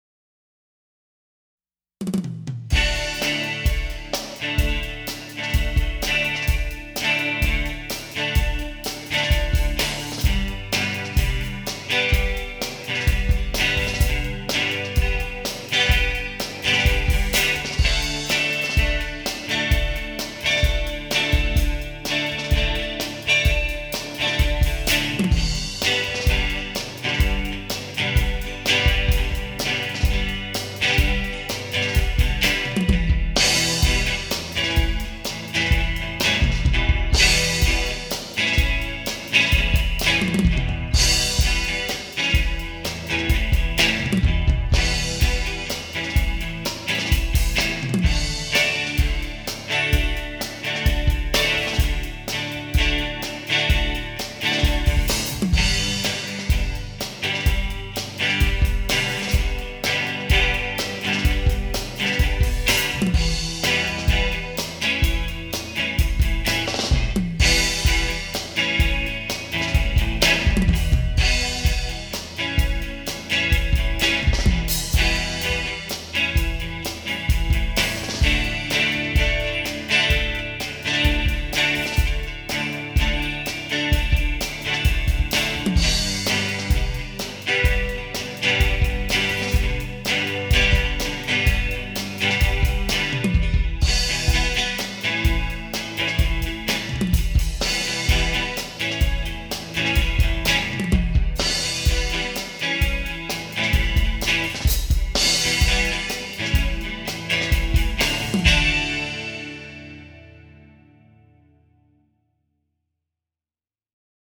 That would RULE if I could do that, all I can do is cheat and use ProTools.
guitar01_week02.mp3